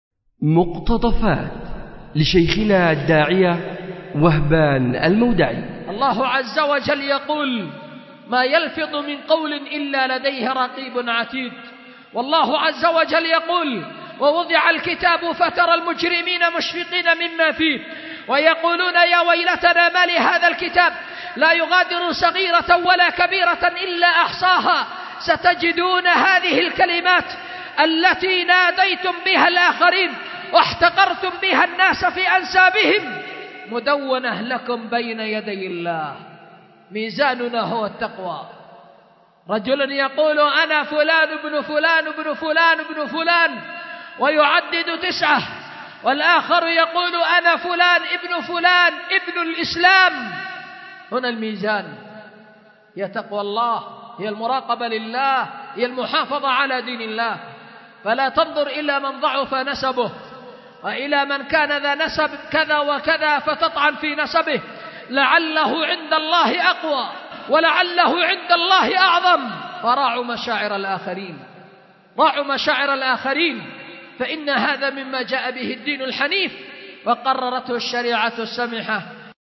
أُلقي بدار الحديث للعلوم الشرعية بمسجد ذي النورين ـ اليمن ـ ذمار ـ 1444هـ